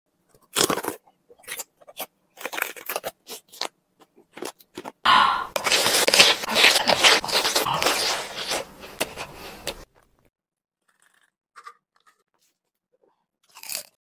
Random color food mukbang Korean sound effects free download
ASMR Testing mukbang Eating Sounds